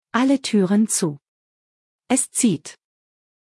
MSFS_Simbrief/Announcements/BAW/ArmDoors.ogg at 6f0ff4bc00ecdf92f3e5728fded5a179f67ae3eb
ArmDoors.ogg